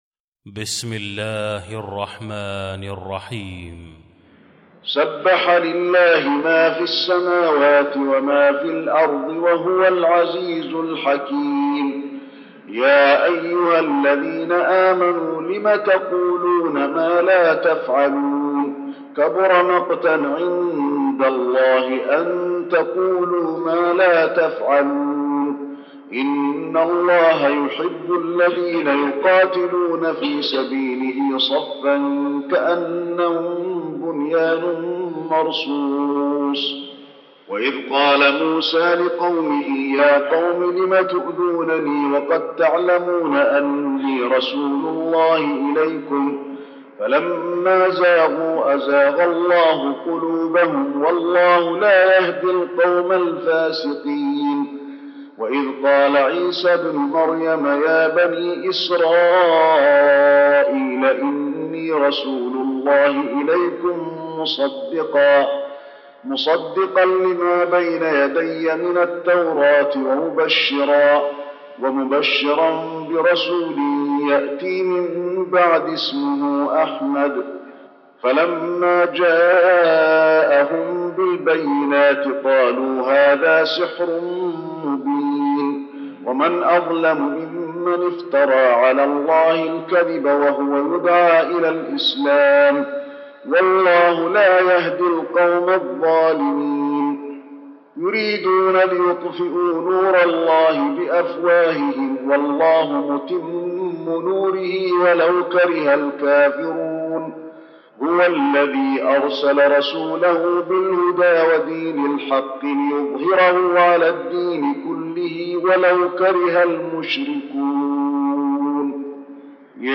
المكان: المسجد النبوي الصف